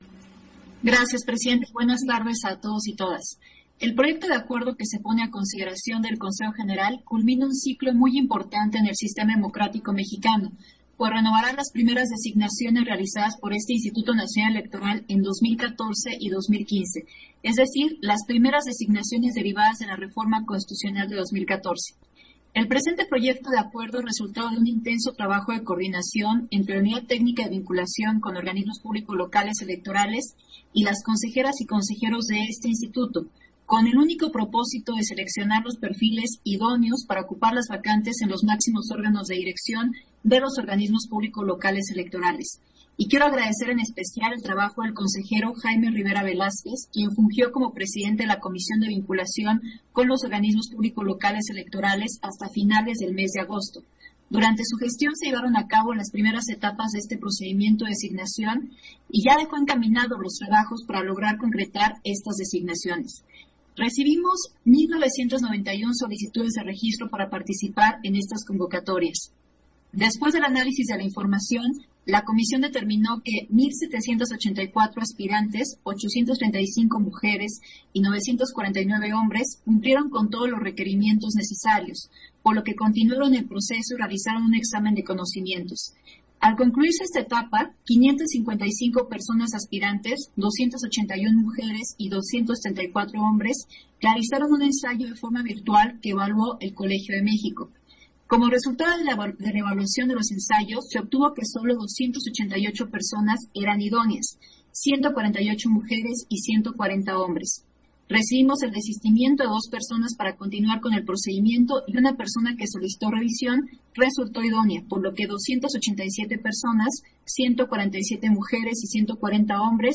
Intervención de Dania Ravel, en Sesión Extraordinaria, por el que se aprueban las propuestas de designación de las presidencias de los Organismos Públicos Locales